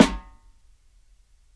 SNARE GHOST.wav